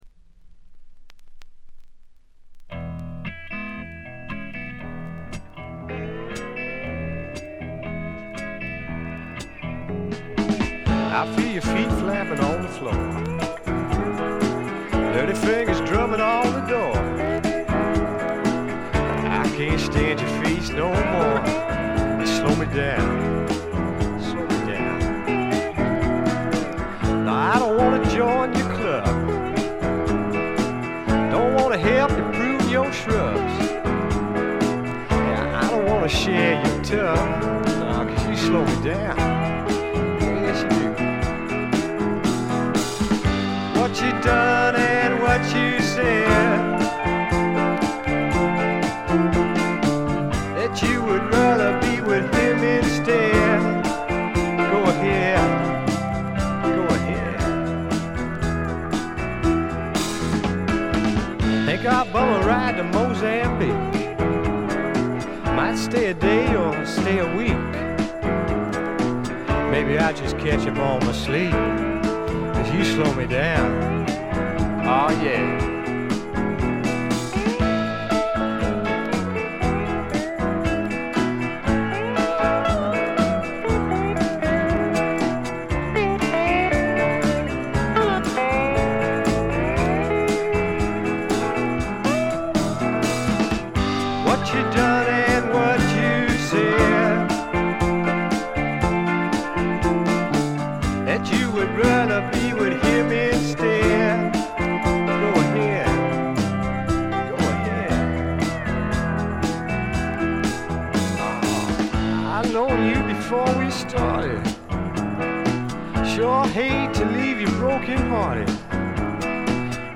ところどころでチリプチ。散発的なプツ音も少々。
カントリー風味、オールド・ロックンロールを元にスワンプというには軽い、まさに小粋なパブロックを展開しています。
試聴曲は現品からの取り込み音源です。